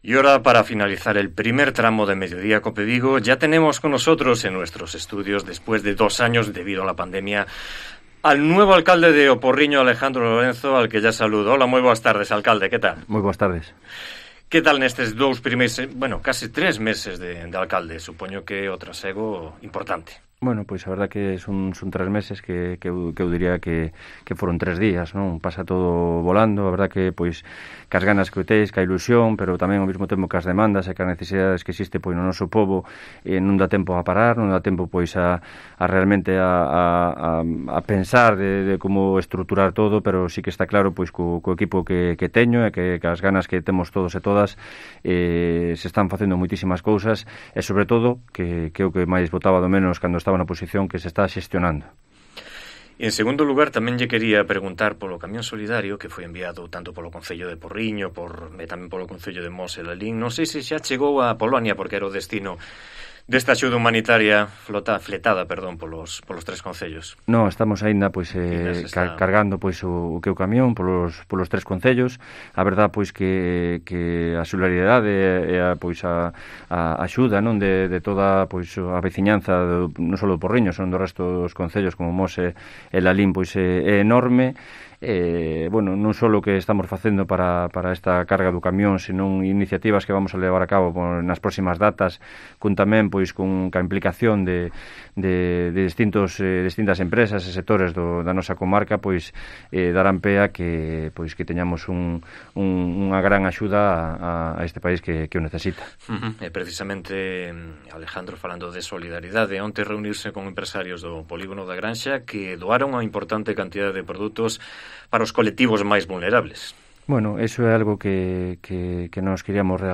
Entrevista con Alejandro Lorenzo, alcalde de O Porriño